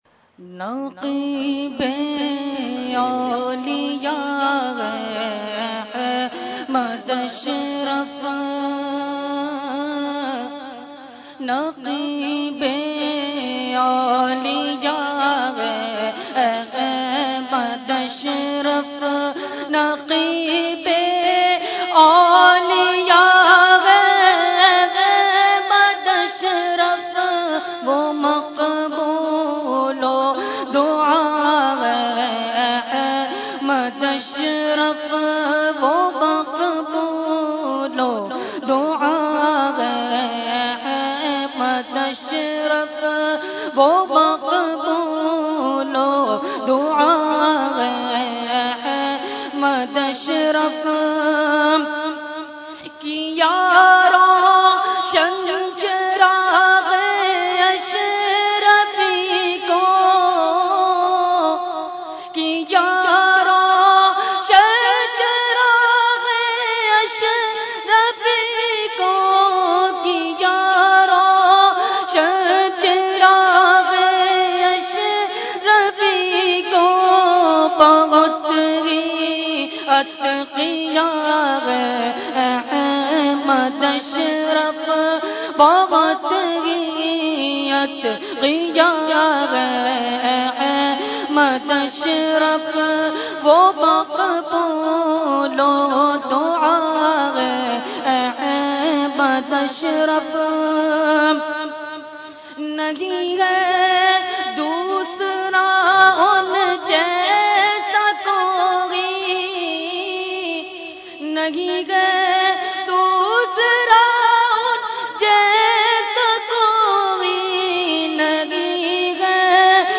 Category : Manqabat | Language : UrduEvent : Urs Ashraful Mashaikh 2015